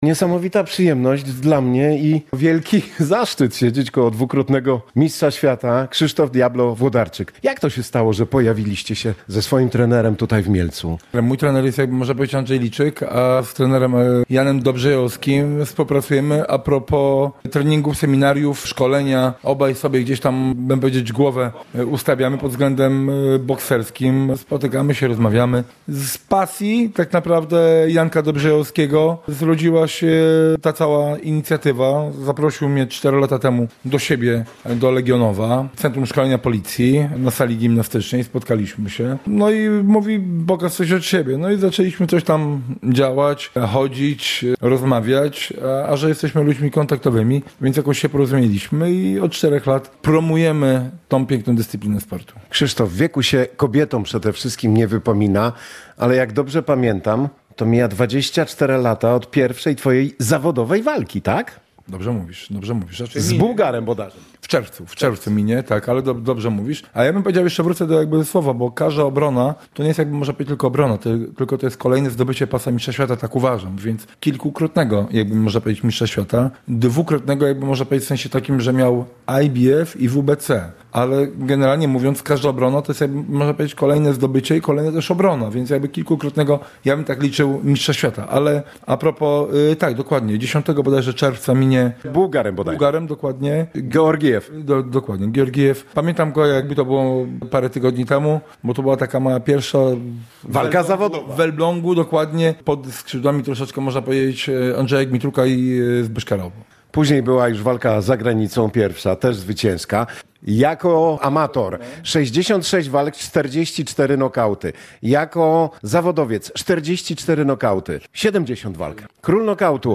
Wywiad-Diablo-Wlodarczyk.mp3